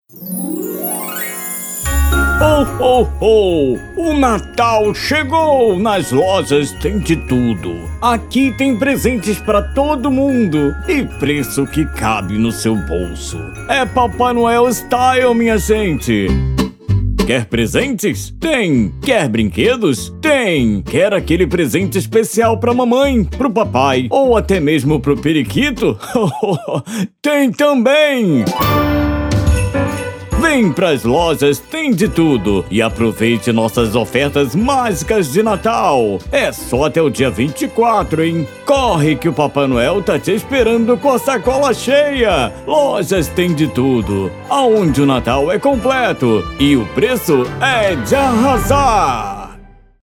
Todos os áudios são produzidos e renderizados na mais alta qualidade e convertidos para o formato que melhor atender suas necessidades.